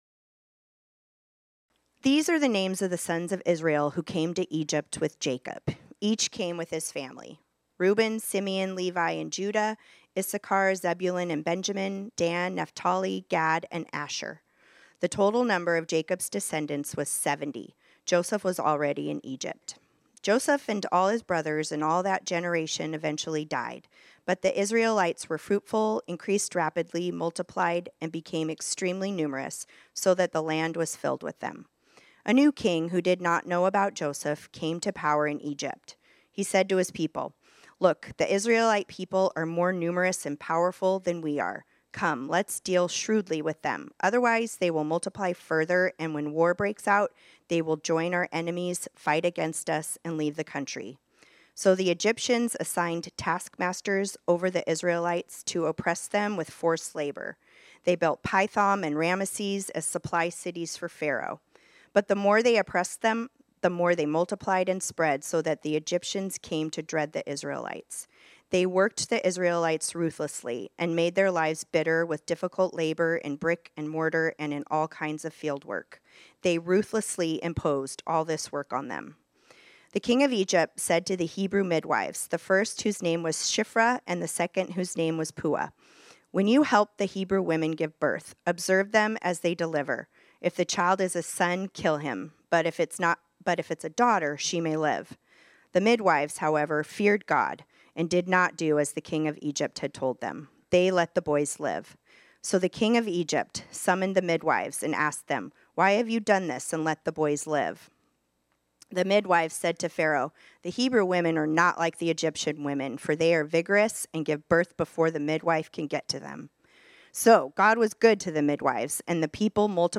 This sermon was originally preached on Sunday, January 5, 2025.